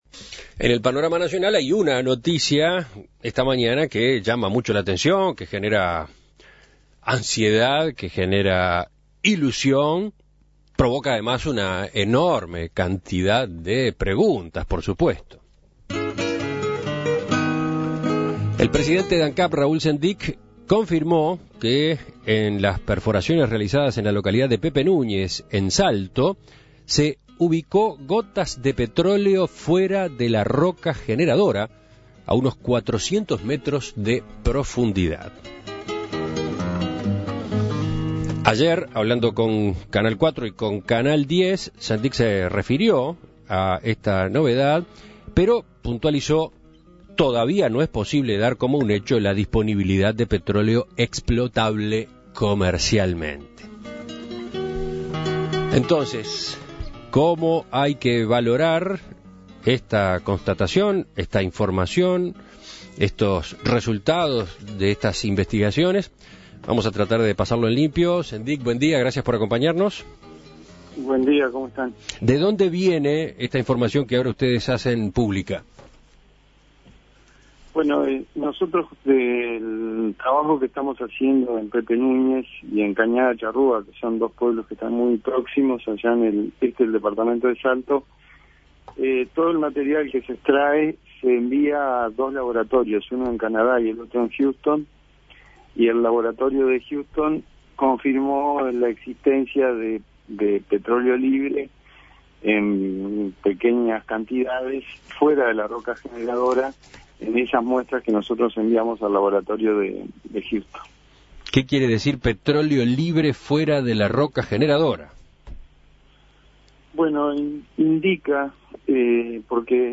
Escuche la entrevista a Raúl Sendic